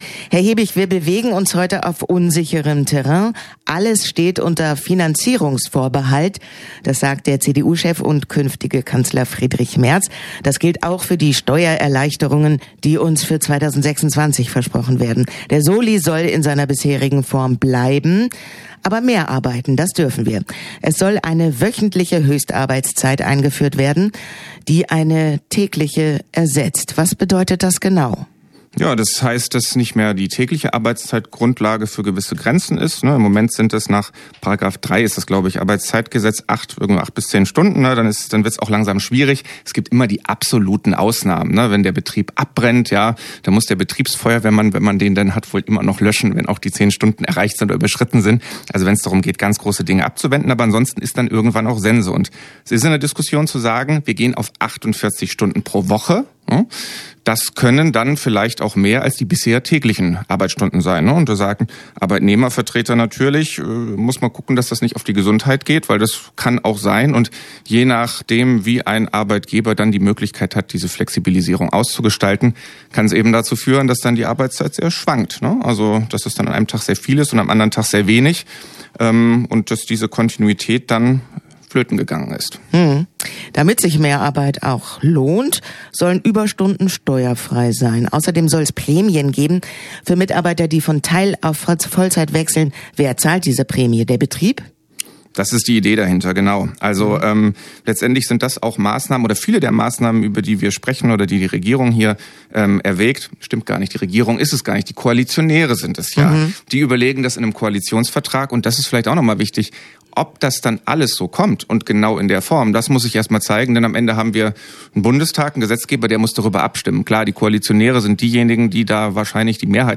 im Gespräch - Okerwelle 104.6